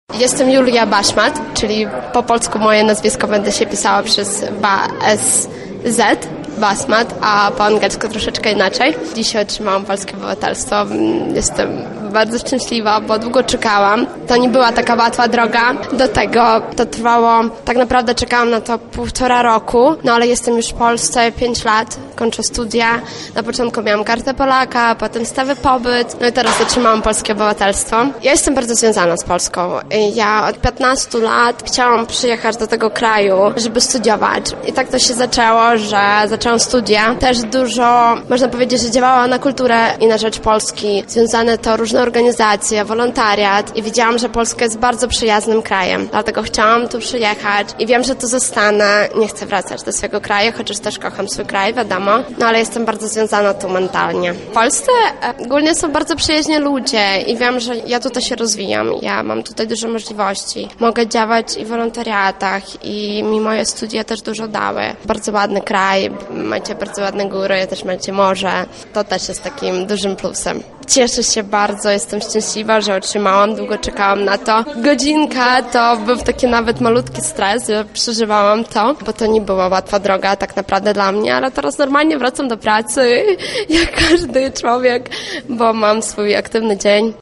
Osiem aktów nadających polskie obywatelstwo wręczył w Sali Kolumnowej Lubelskiego Urzędu Wojewódzkiego wicewojewoda Robert Gmitruczuk.
W rozmowie z naszym reporterem przyznali, że to dla nich szczególny dzień.